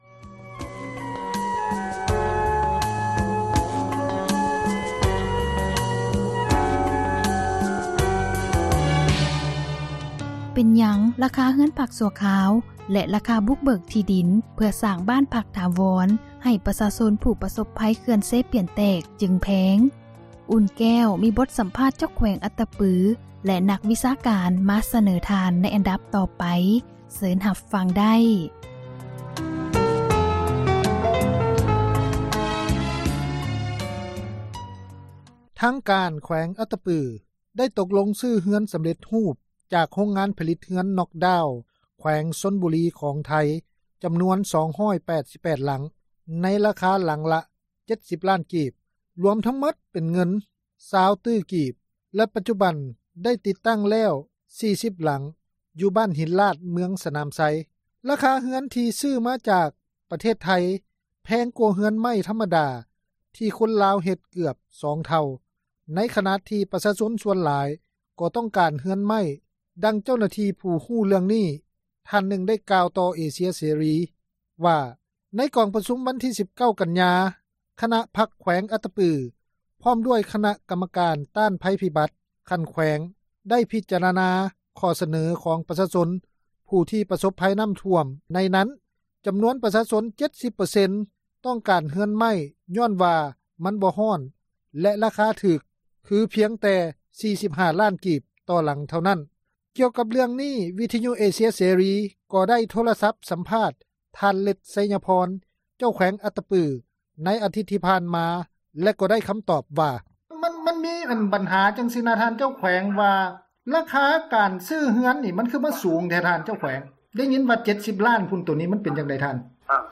ກ່ຽວກັບເຣື້ອງນີ້ ວິທຍຸເອເຊັຍເສຣີ ກໍໄດ້ໂທຣະສັບ ສຳພາດ ທ່ານ ເລັດ ໄຊຍະພອນ ເຈົ້າແຂວງອັດຕະປື ໃນອາທິດທີ່ຜ່ານມາ.